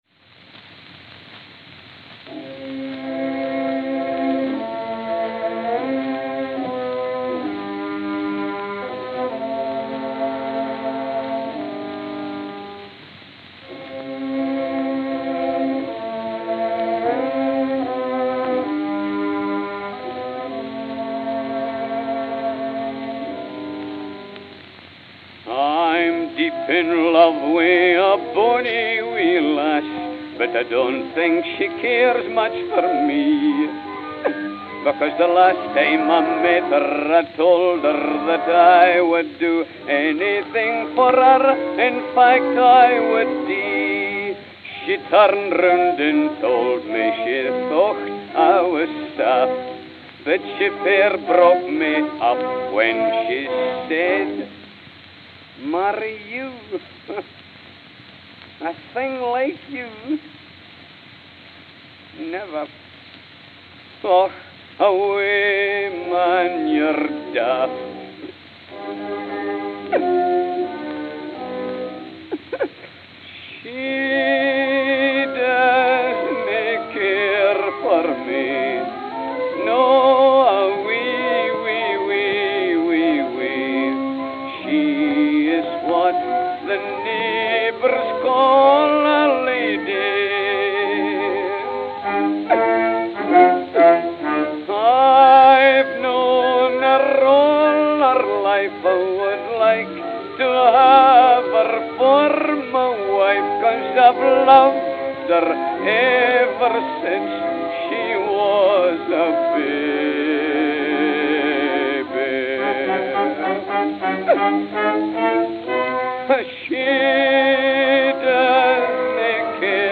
December 12, 1909 (Camden, New Jersey) (15/24)